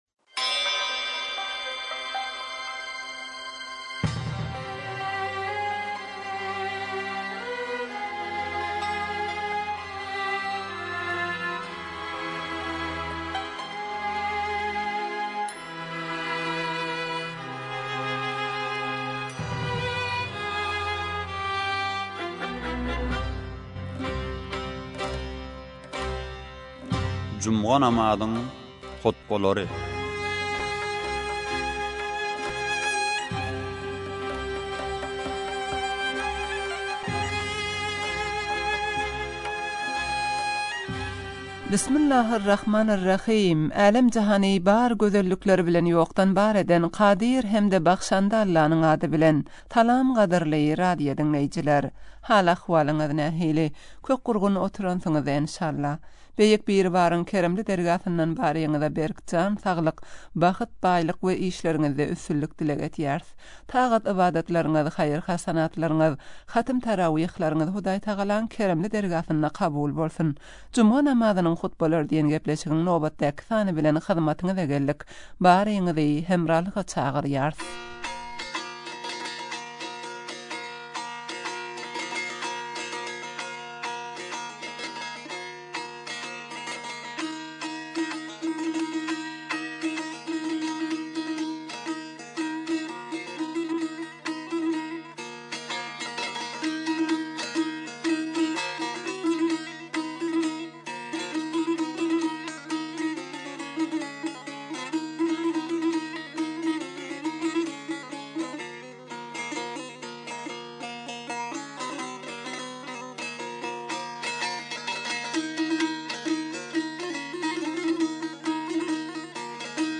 juma namazyň hutbalary